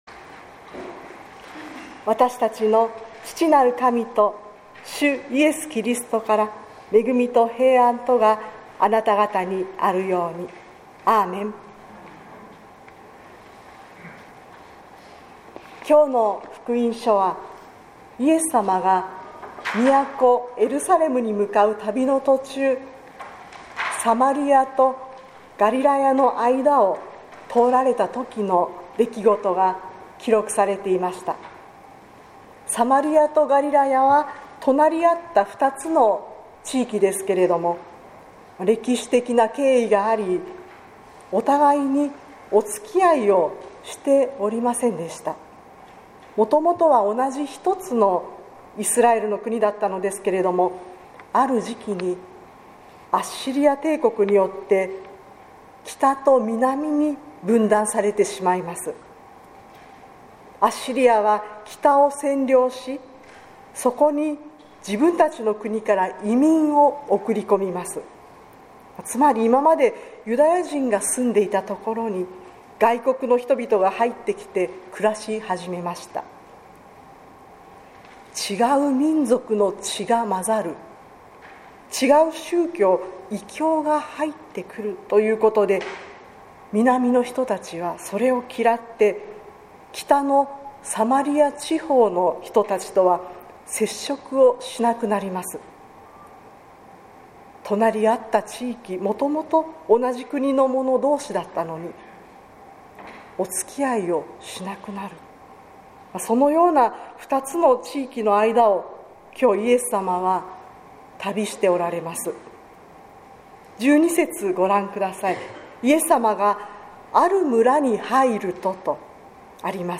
説教「一線を超えて」（音声版）